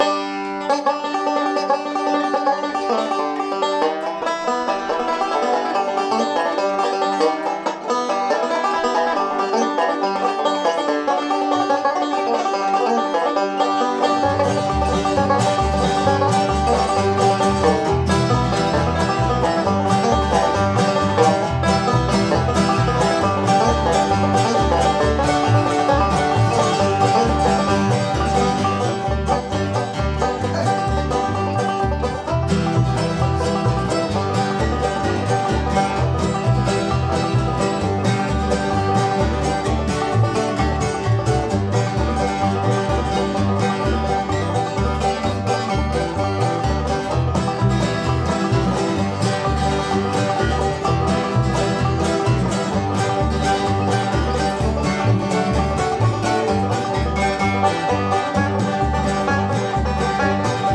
The Woodshop Jam is a weekly indoor/outdoor all acoustic music jam. We play mostly bluegrass and old time, but also country, folk, rock and whatever else we feel like.
Our regular instruments include upright bass, guitars, banjos, resonator guitars, mandolins, fiddles and more.